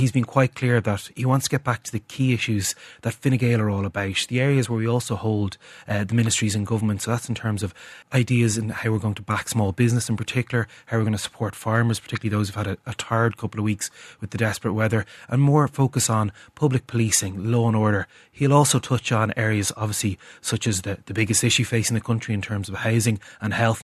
Junior Minister Neale Richmond says the speech will be broad: